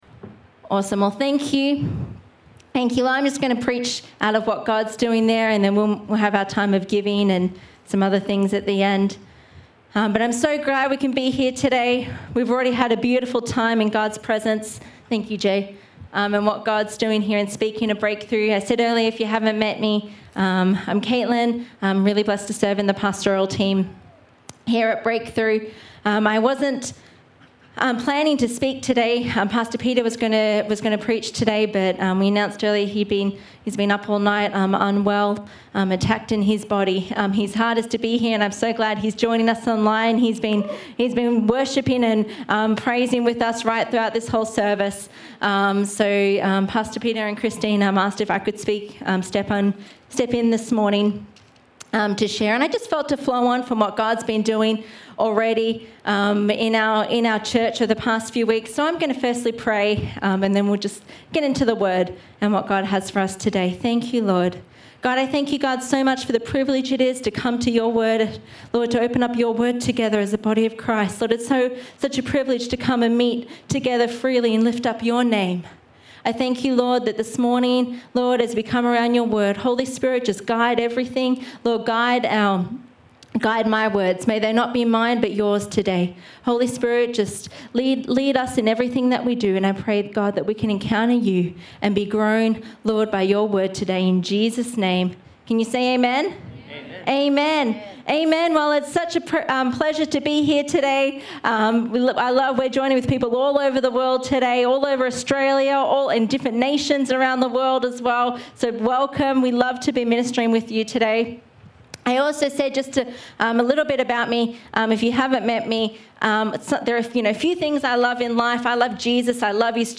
A Christmas Sermon...In MARCH?